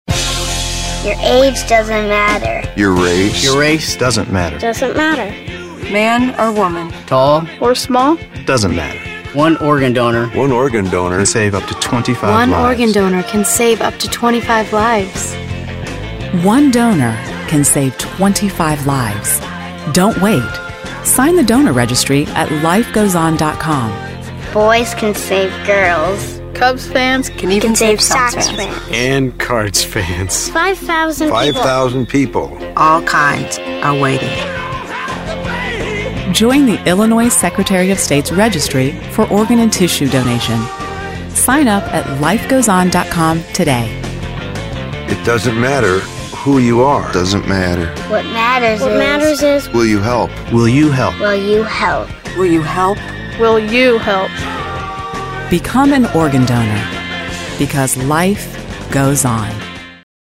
The PSA features the song, “Join Together” by The Who.